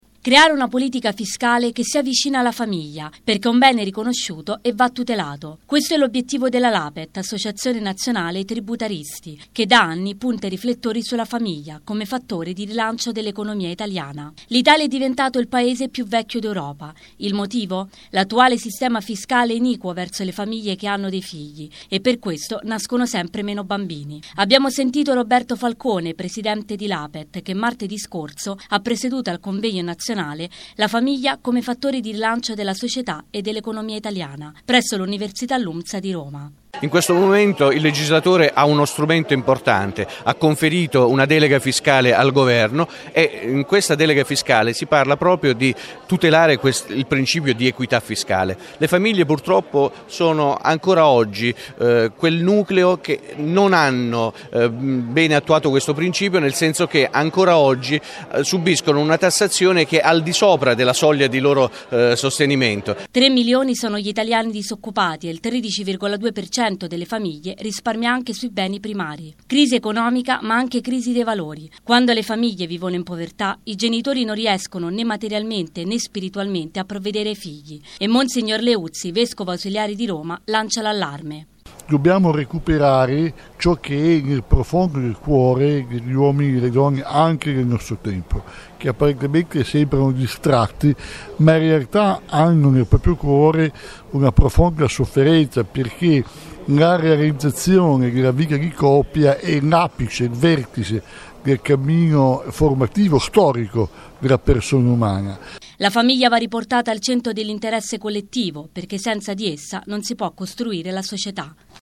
convegno-Famiglia.mp3